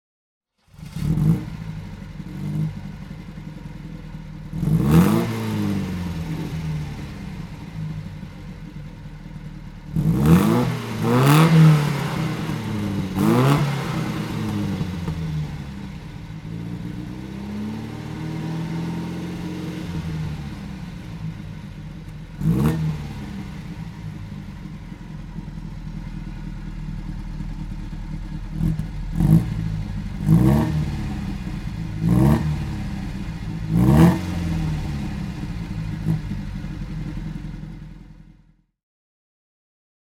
Motorsounds und Tonaufnahmen zu Alfa Romeo Fahrzeugen (zufällige Auswahl)
Alfa Romeo Junior Z (1972) - Starten und Leerlauf
Alfa_Romeo_Junior_Z_1972.mp3